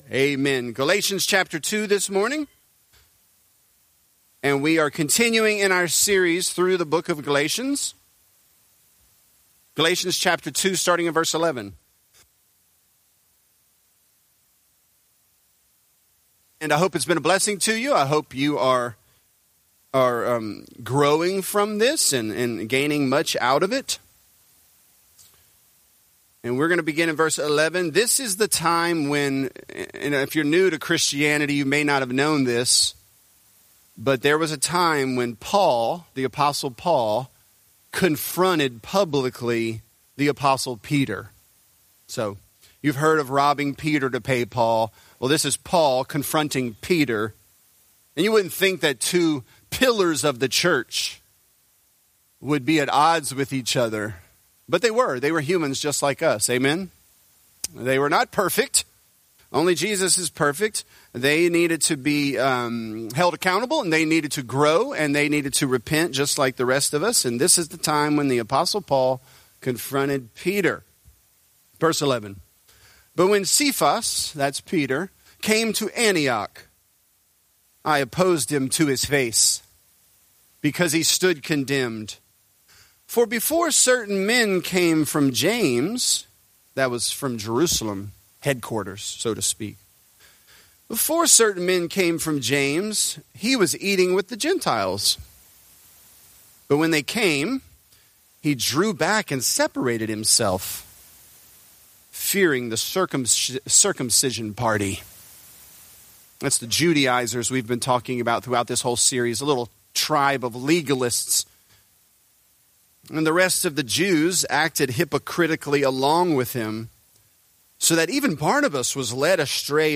Galatians: An Anxiety-Driven Life | Lafayette - Sermon (Galatians 2)